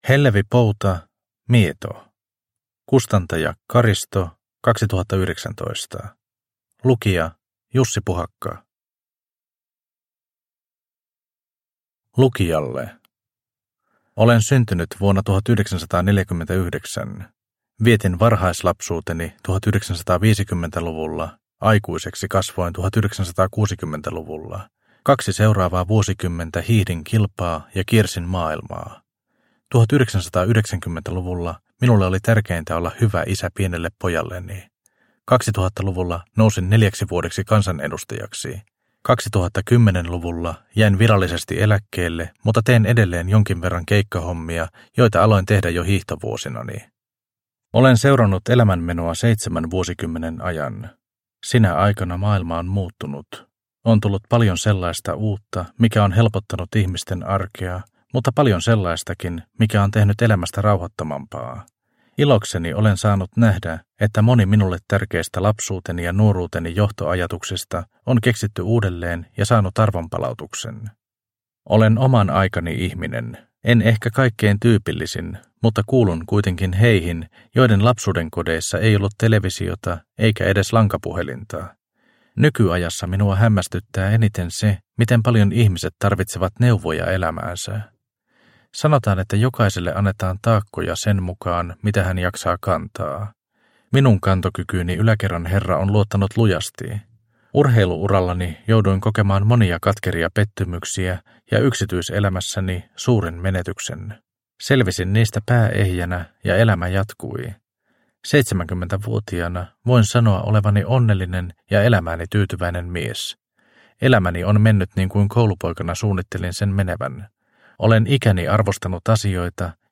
Mieto – Ljudbok – Laddas ner